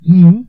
描述：Todos los sonidos vocales hechos por los personajes secundarios del corto